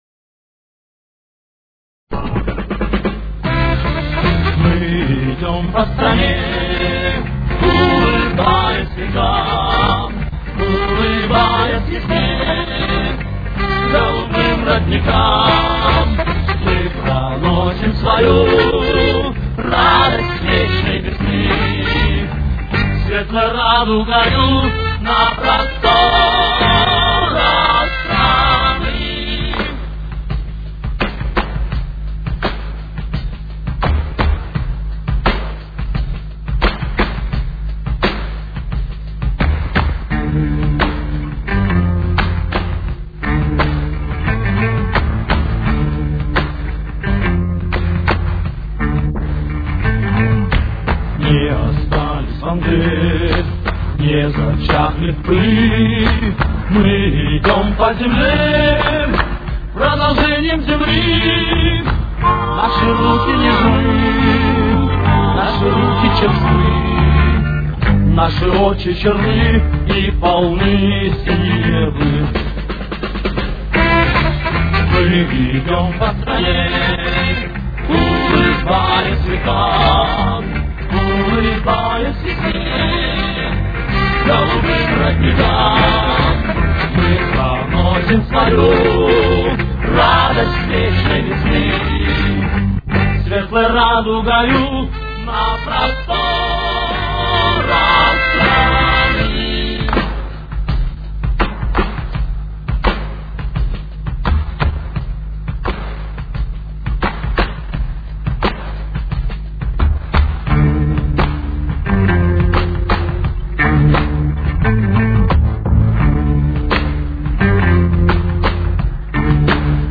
с очень низким качеством (16 – 32 кБит/с)
Тональность: Фа мажор. Темп: 168.